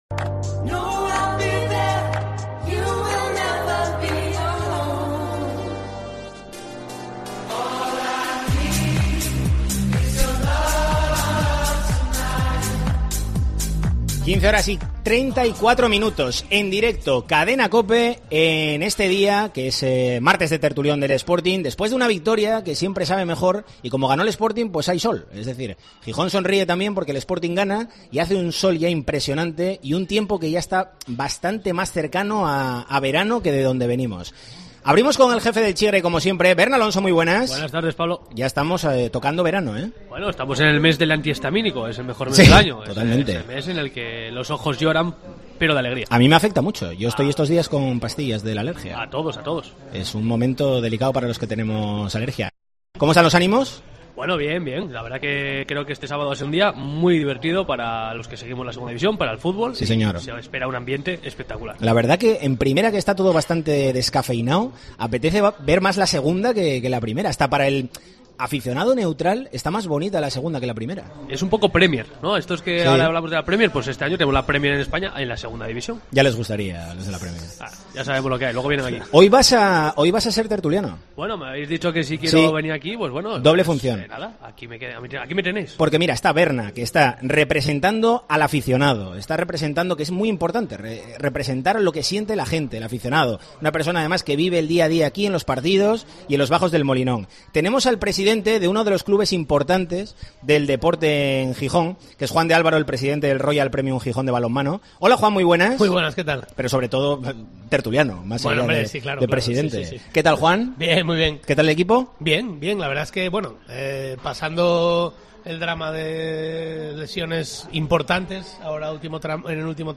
En el capítulo de este martes de 'El Tertulión del Sporting' en Deportes COPE Asturias debatimos acerca de las claves de un posible ascenso: el clima en el sportinguismo, el momento del equipo o las decisiones de Ramírez .